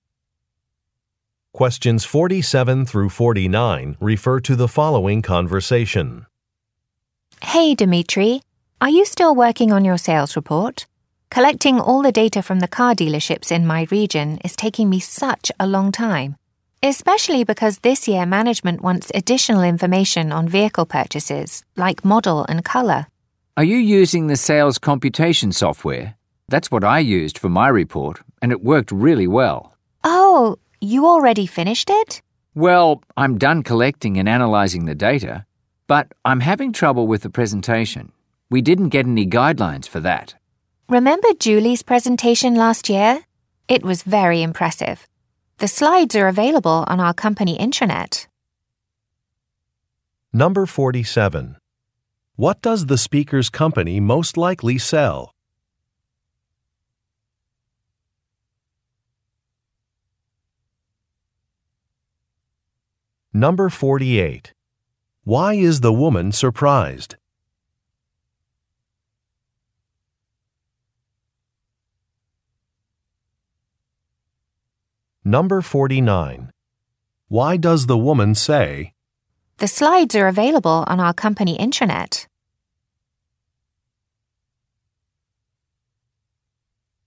Question 47 - 49 refer to following conversation:
Part III: Short Conversations